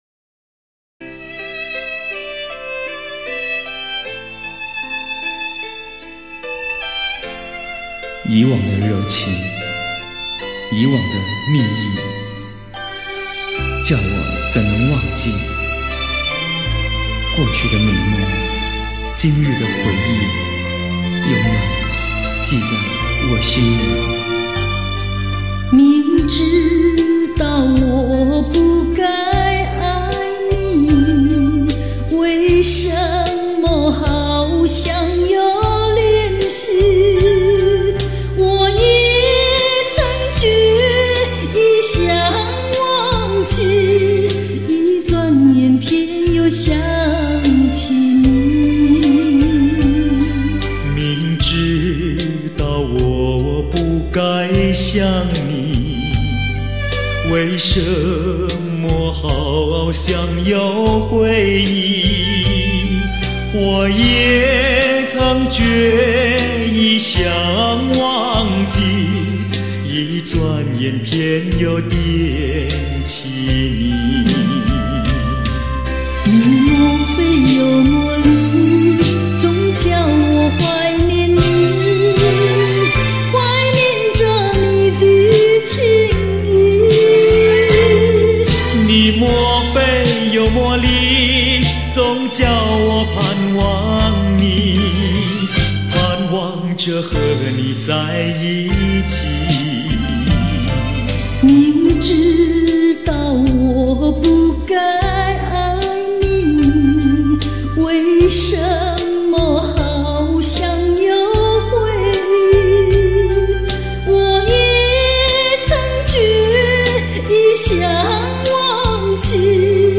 龙腔雅韵 婉转悦耳 醉人心扉